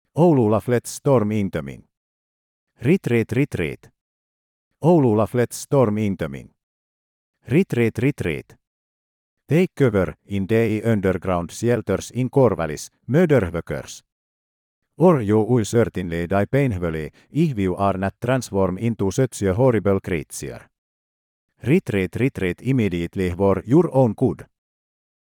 Made using the same text-to-speech system as ASBOWAAT.mp3.